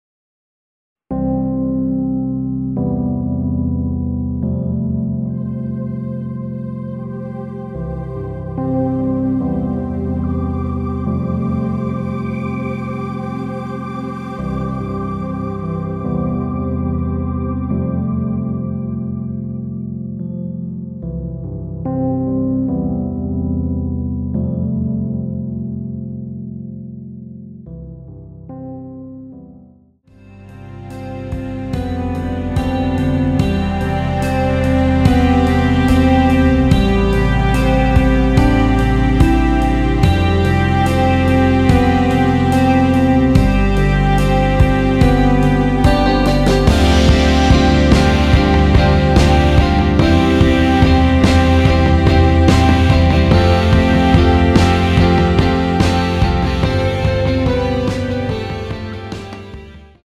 원키에서(-2)내린 MR입니다.
앞부분30초, 뒷부분30초씩 편집해서 올려 드리고 있습니다.